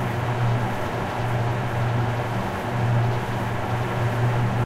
scifi_nodes_ambience_vent.ogg